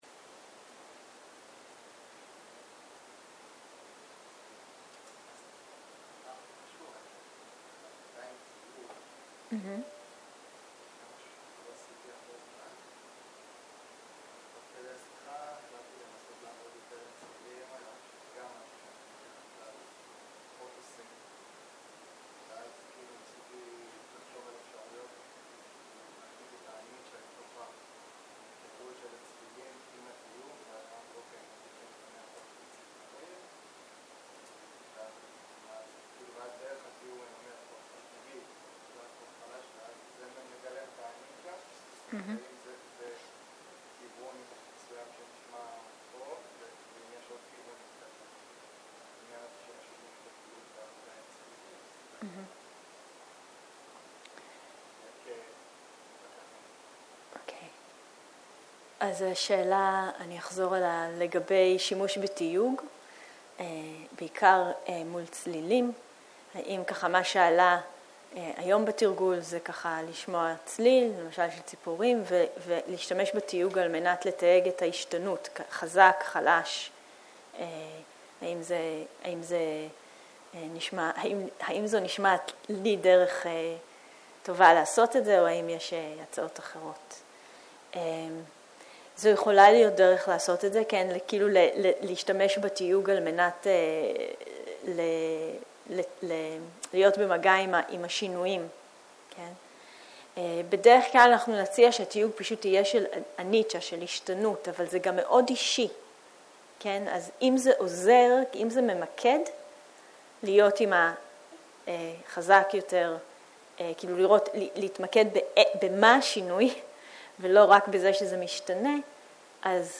בוקר - שאלות ותשובות
סוג ההקלטה: שיחת פתיחה